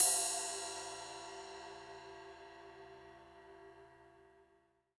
D2 RIDE-02.wav